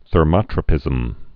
(thər-mŏtrə-pĭzəm)